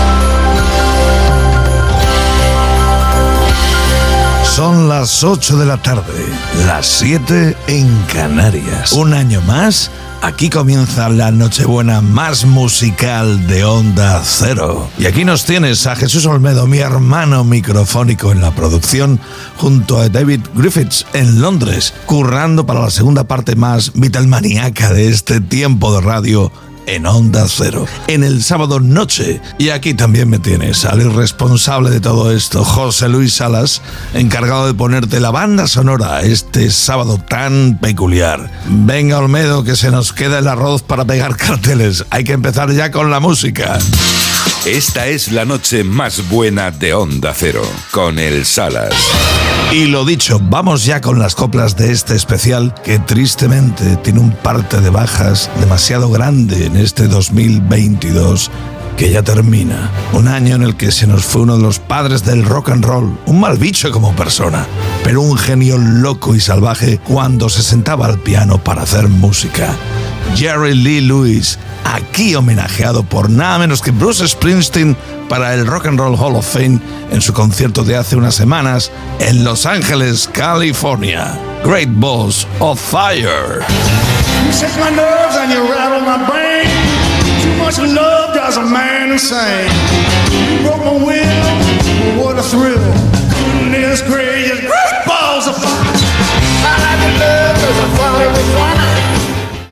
Presentació, indicatiu, presentació del primer tema musical
Musical